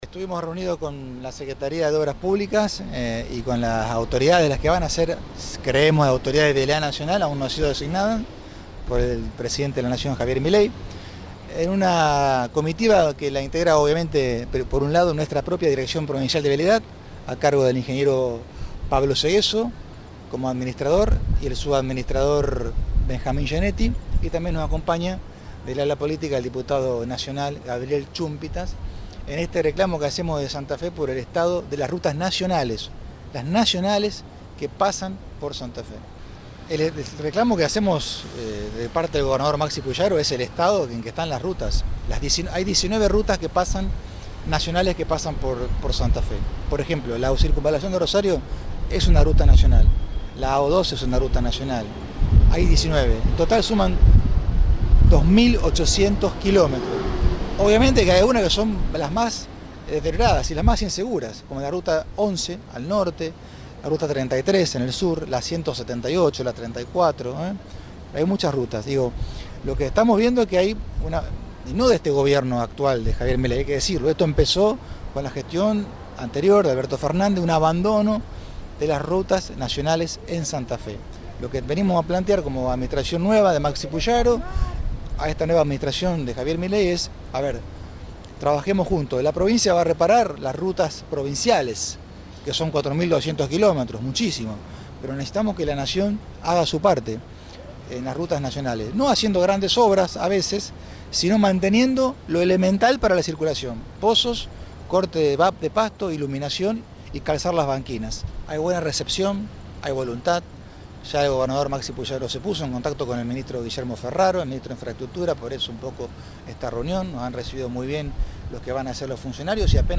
Escucha la palabra de Lisandro Enrico en Radio EME: